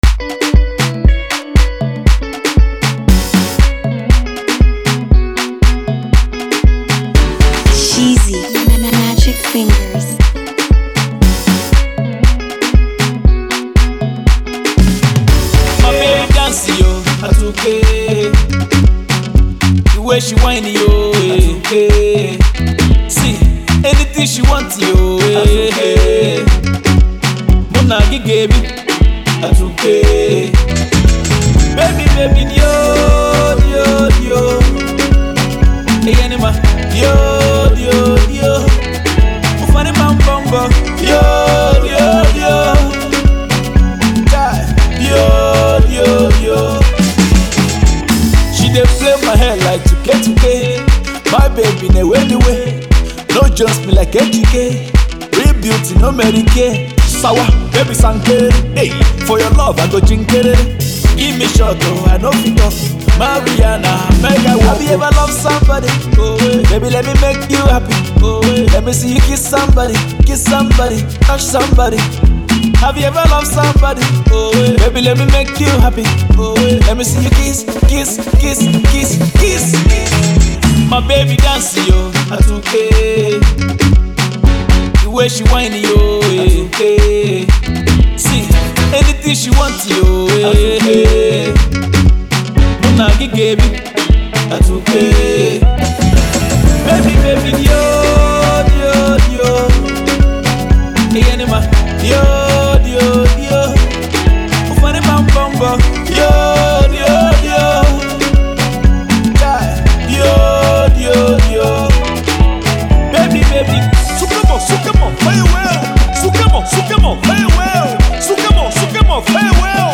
a calm vibe with heavy instrumentals.